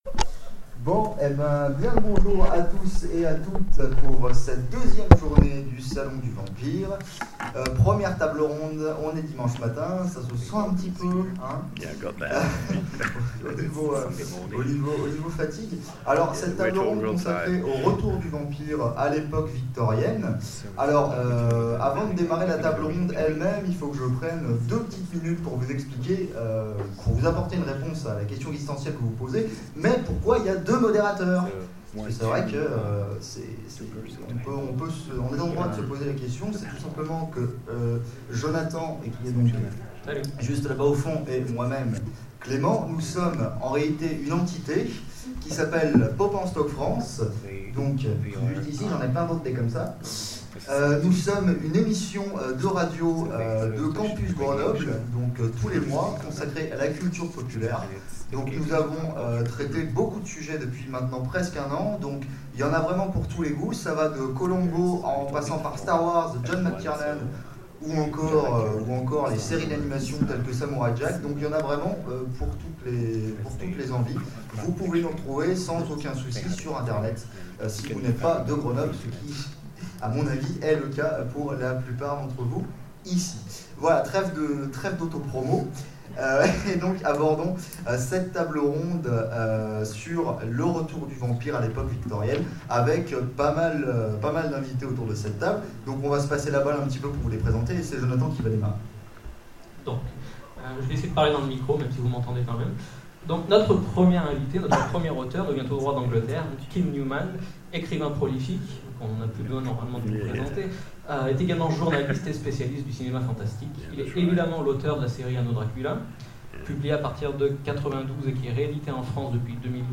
Salon du vampire 2016 : Conférence Le retour des vampires victoriens